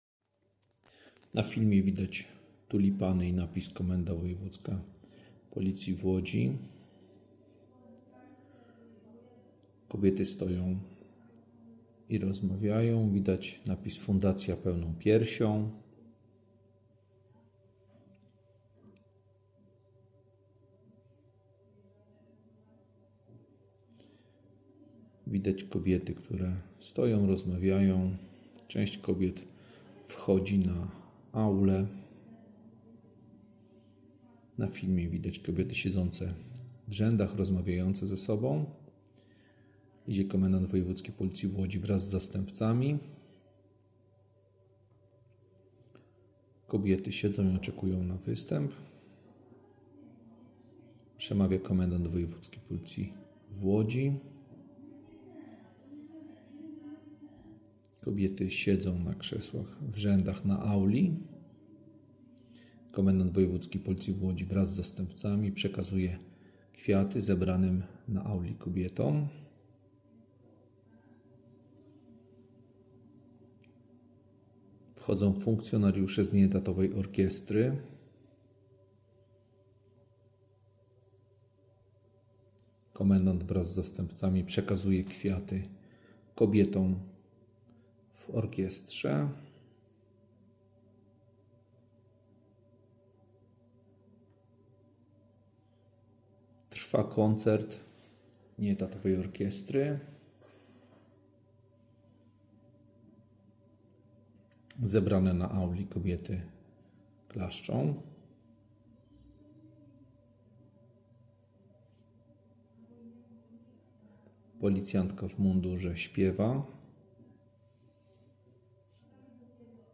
Z tej okazji w Komendzie Wojewódzkiej Policji w Łodzi odbył się uroczysty koncert, podczas którego funkcjonariuszki oraz pracownice cywilne otrzymały serdeczne życzenia i wyrazy uznania za swoją codzienną służbę i pracę.
Nadinspektor Arkadiusz Sylwestrzak złożył Paniom serdeczne życzenia z okazji ich święta.
Uroczysty koncert Nieetatowej Orkiestry Komendy Wojewódzkiej Policji w Łodzi